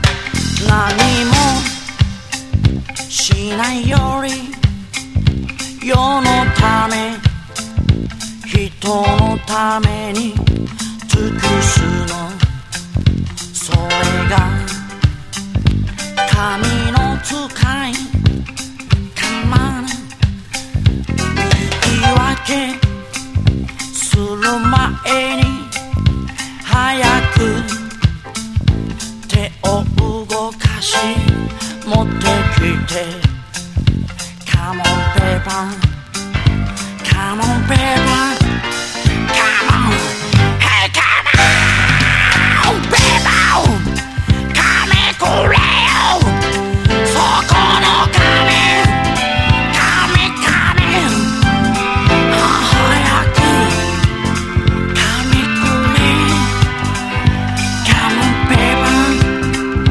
STEREO CHECK / LOUNG / EASY LISTENING
A面には軽快なポップ・ラウンジ、B面にはストリングスを主体としたオーケストラ作品を収録しています。